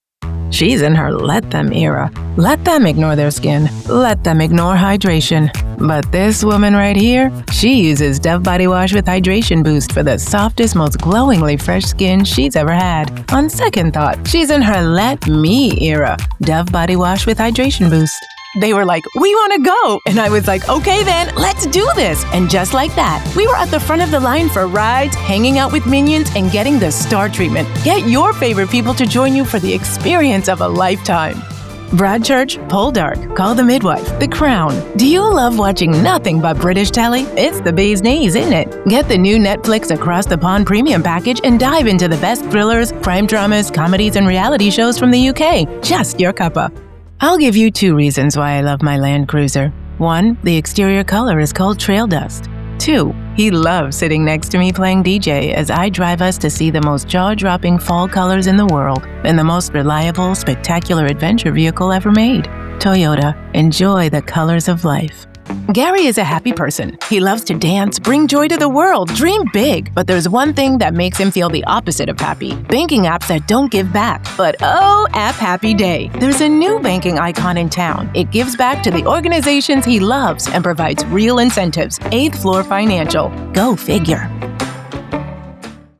Conversacional
Corporativo